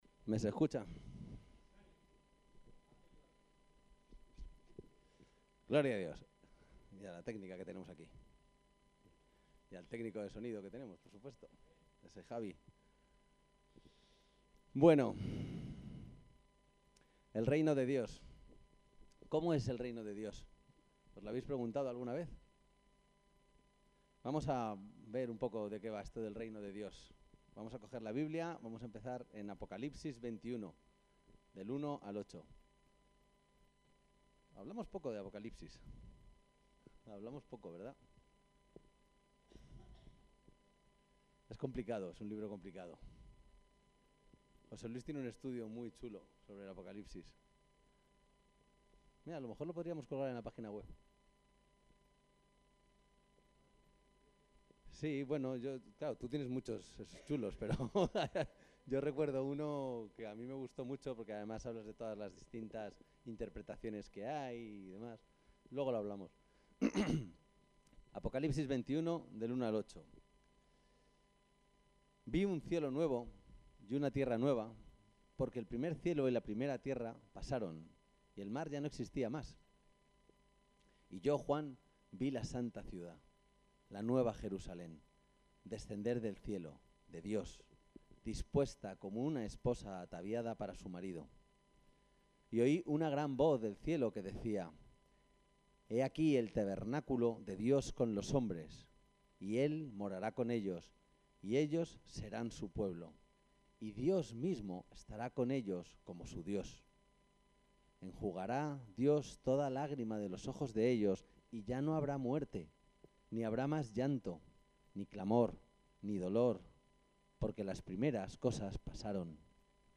El texto de la predicación El Reino de Dios